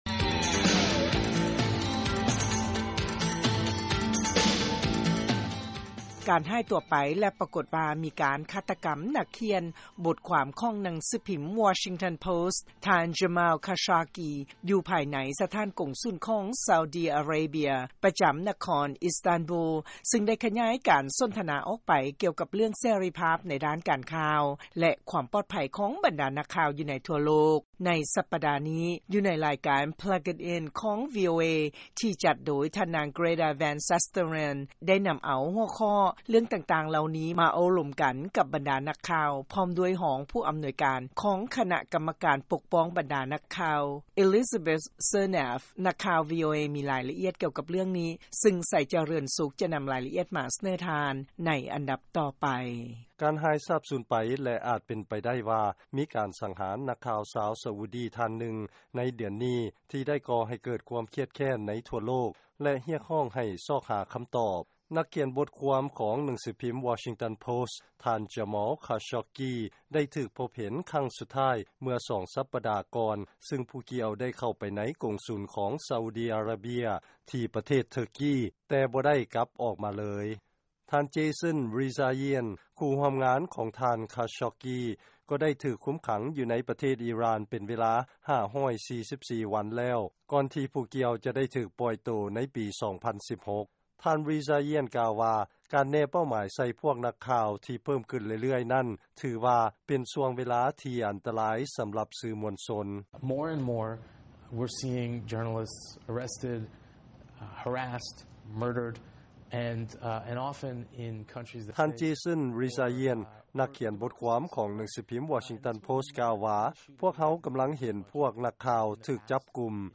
ເຊີນຟັງລາຍງານ ອົງການສື່ມວນຊົນ ແລະ ນັກຂ່າວທັງຫຼາຍ ໃນທົ່ວໂລກ ກຳລັງຖືກຄຸກຄາມ ຢ່າງໜັກ